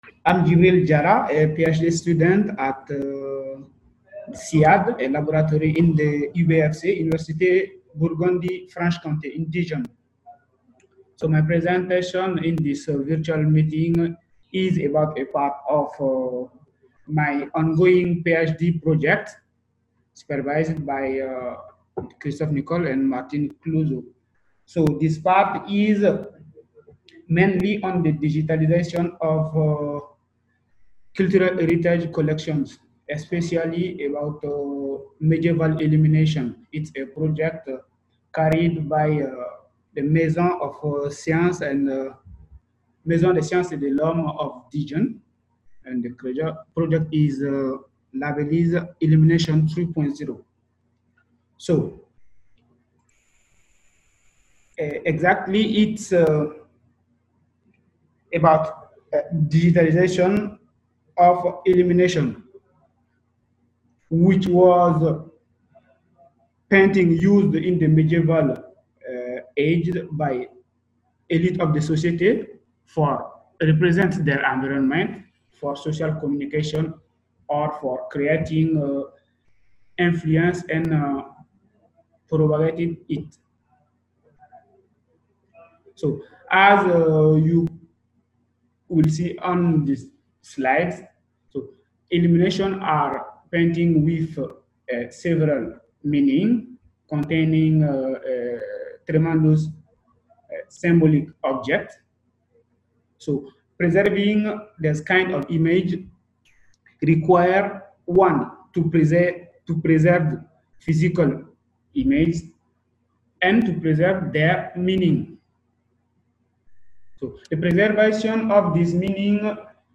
Flash talk